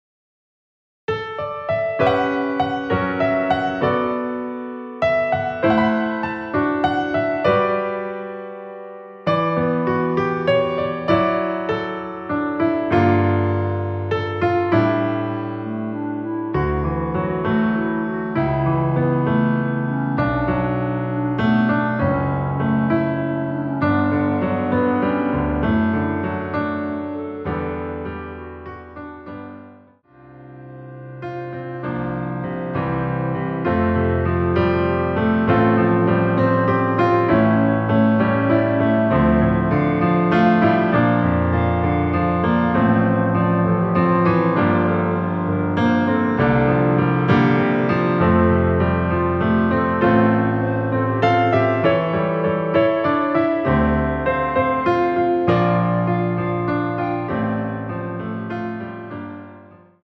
멜로디 포함된 MR 입니다.
◈ 곡명 옆 (-1)은 반음 내림, (+1)은 반음 올림 입니다.
앞부분30초, 뒷부분30초씩 편집해서 올려 드리고 있습니다.
중간에 음이 끈어지고 다시 나오는 이유는